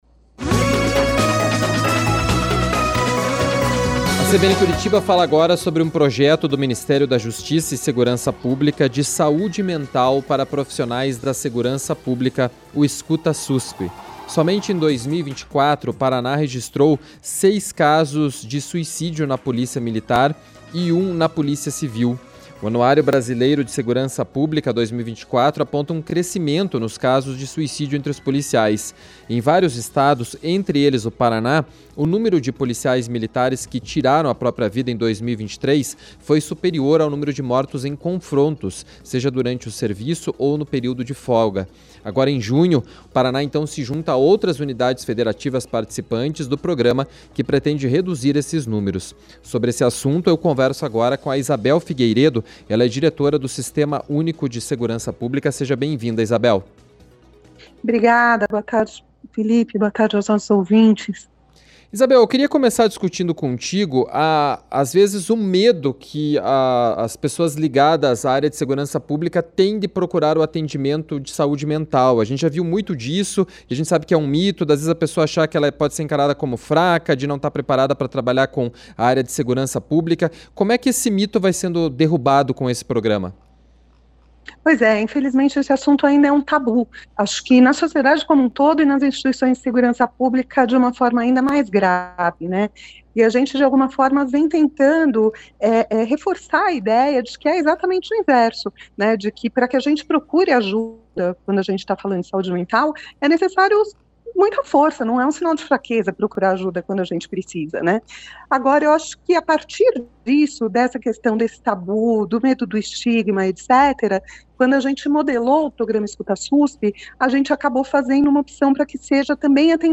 conversou com a Isabel Figueiredo, diretora do Sistema Único de Segurança Pública.